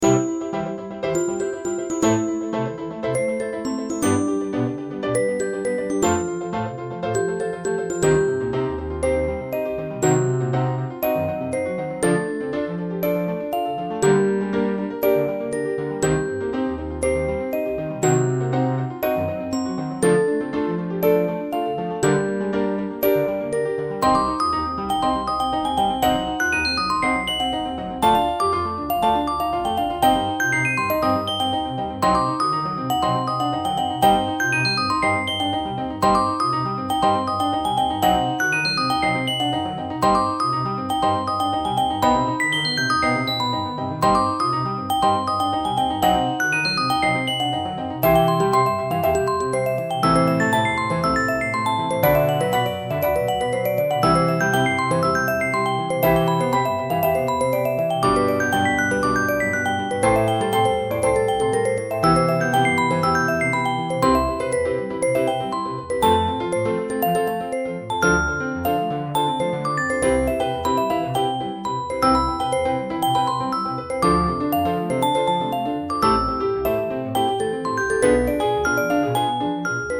ティンクルベル、ピアノ、コントラバス